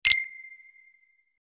menuhit.mp3